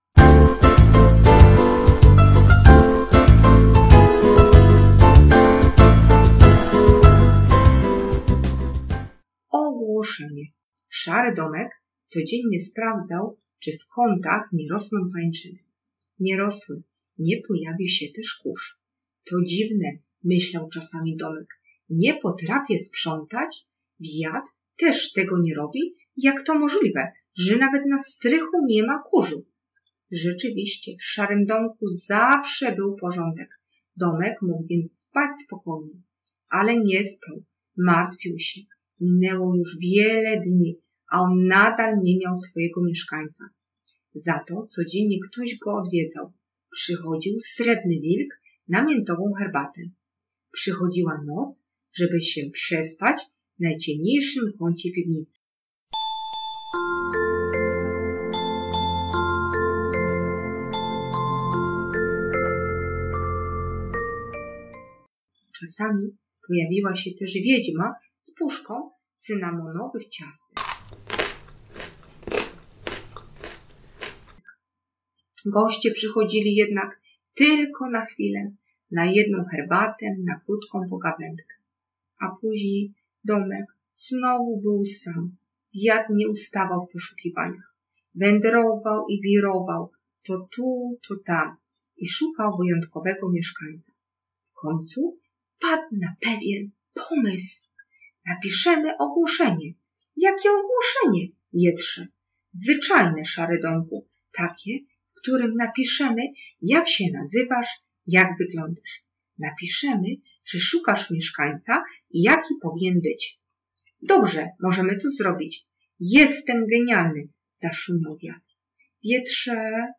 Na Dzień Dziecka dwa rozdziały audiobooka